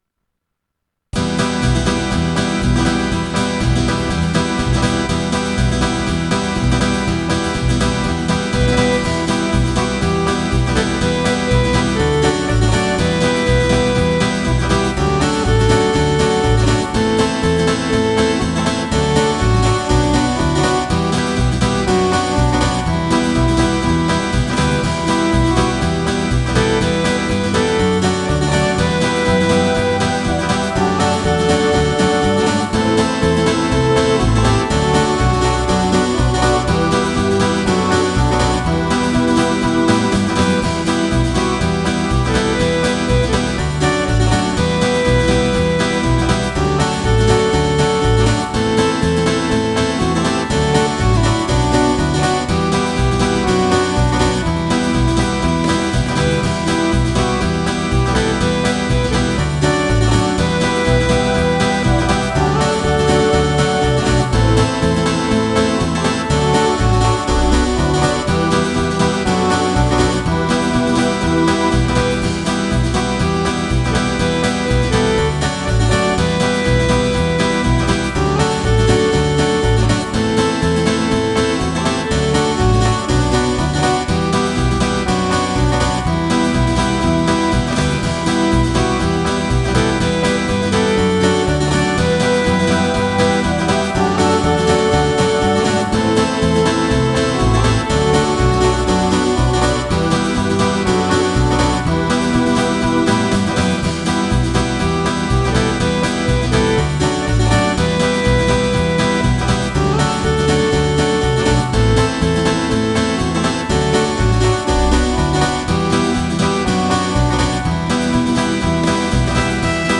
• Le fichier son, AVEC la mélodie ==>